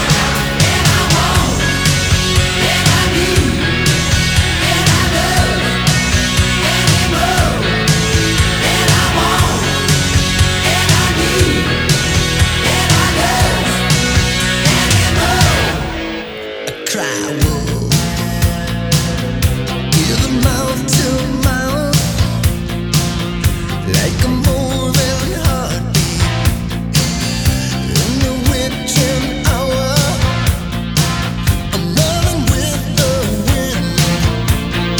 Жанр: Пост-хардкор / Хард-рок / Рок
# Hard Rock